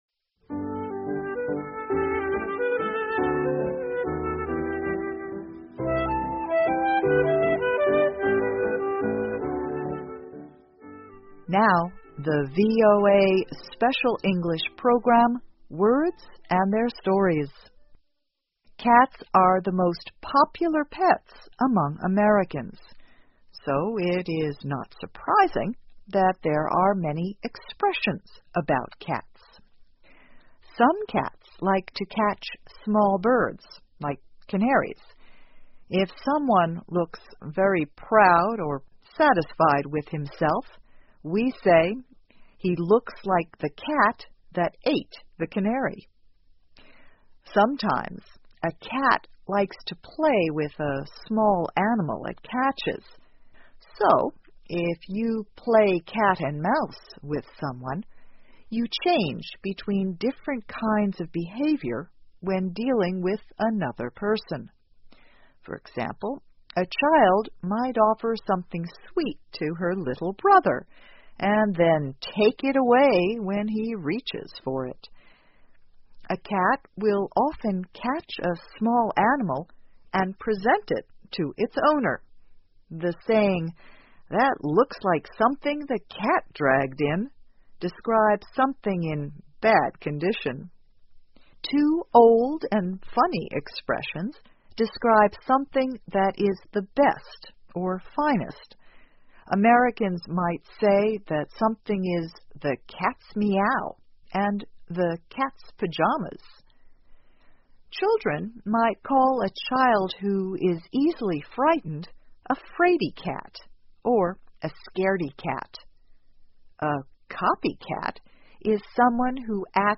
VOA慢速英语2013 词汇典故:关于猫的相关词汇 听力文件下载—在线英语听力室